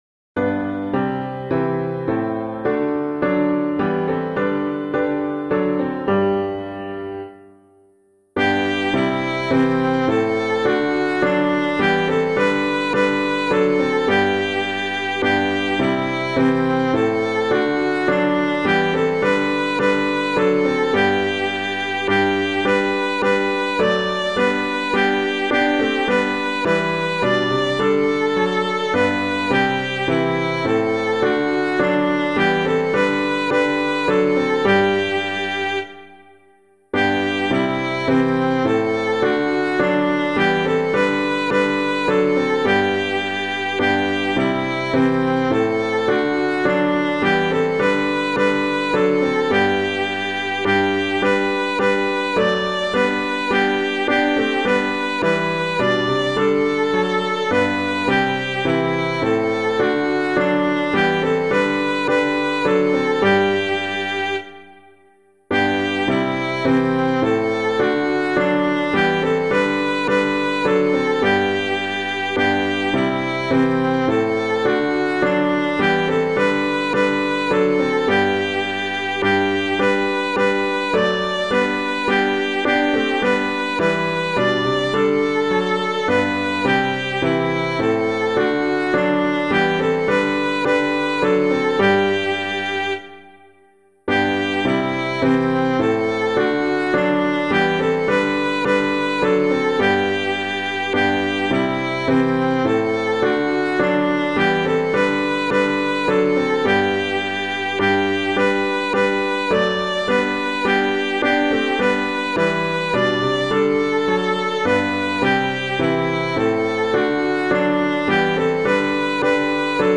Public domain hymn suitable for Catholic liturgy.
Immortal Invisible God Only Wise [Smith - SAINT DENIO] - piano.mp3